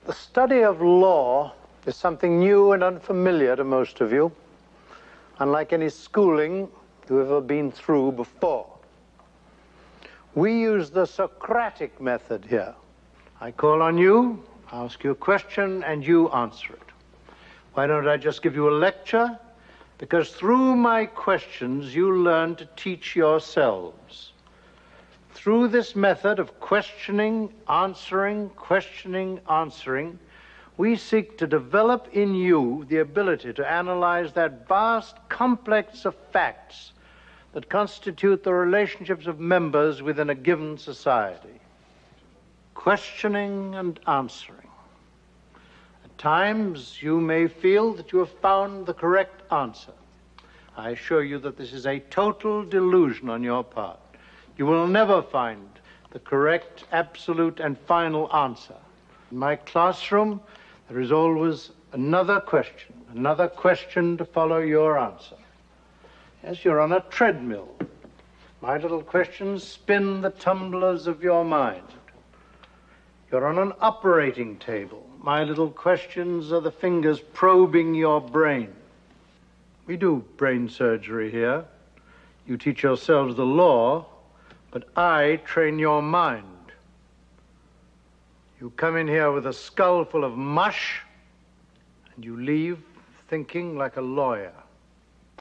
The lecture scene in which Kingsfield explains the Socratic method is quite a universal and can apply about teaching in general.